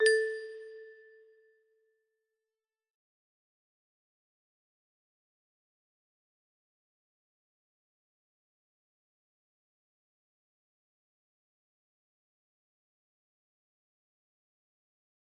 BreakStart music box melody